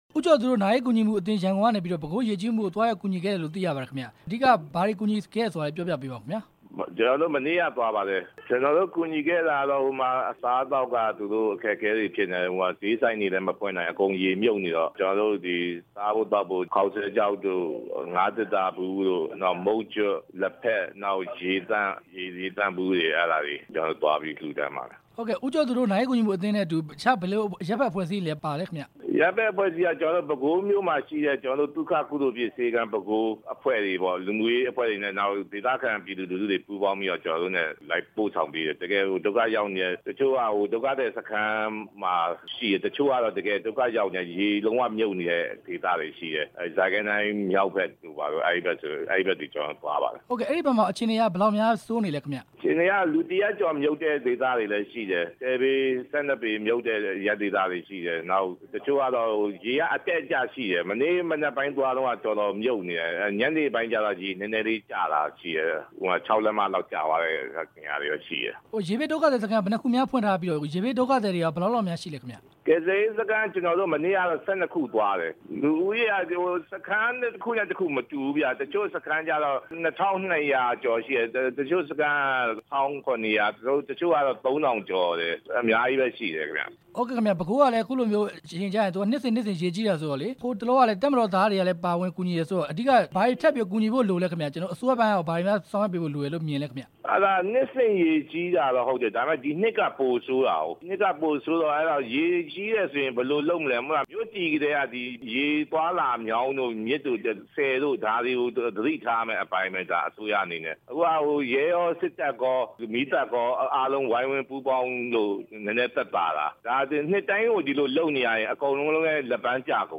ဦးကျော်သူနဲ့ မေးမြန်းချက်